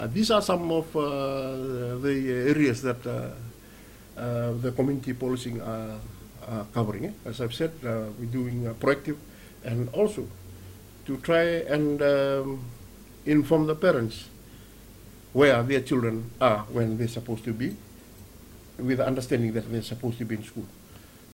Acting Police Commissioner Operation Livai Driu.